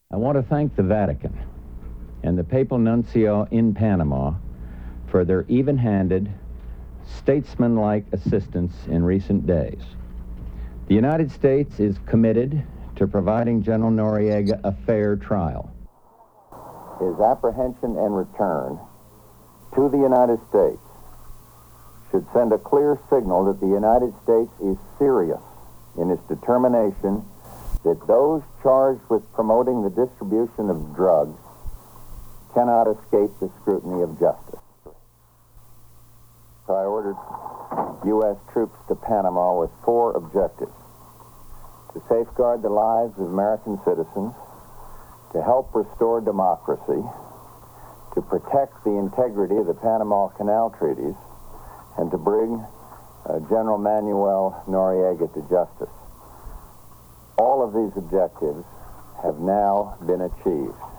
Speakers Bush, George, 1924-2018